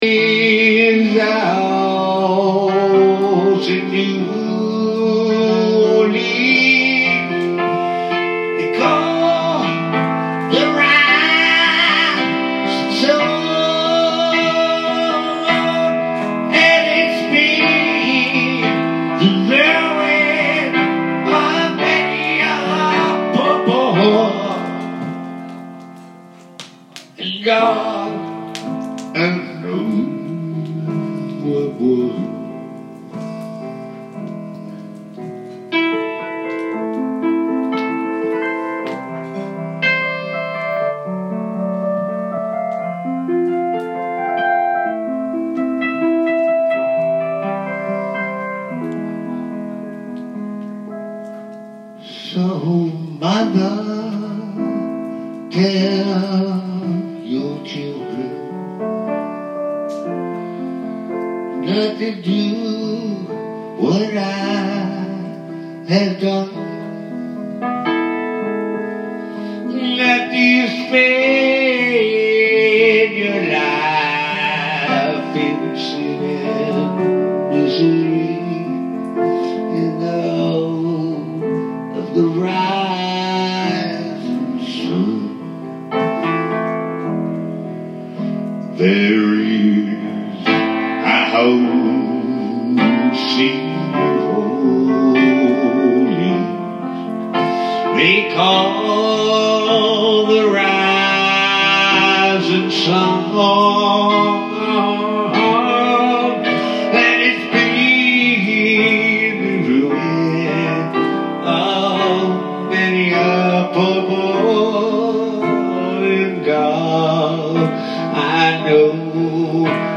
haunting melodies